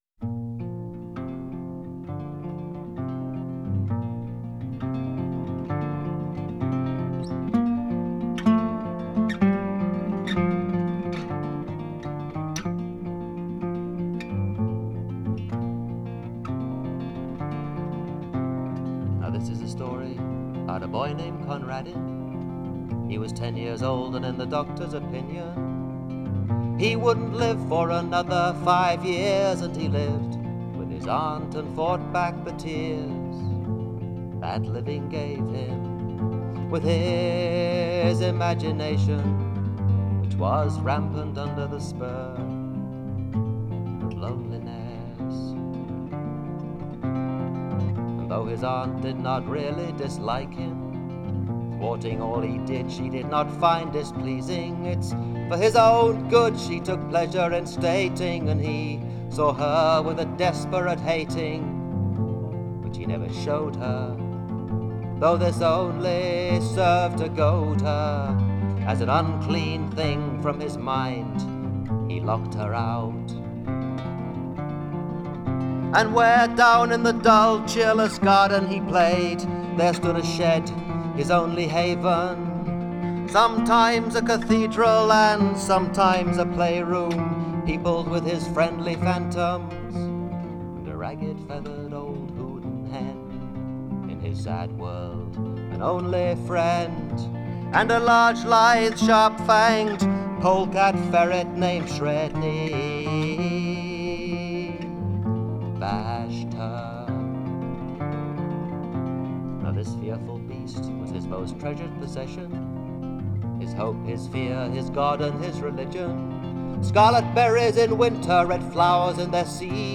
haunting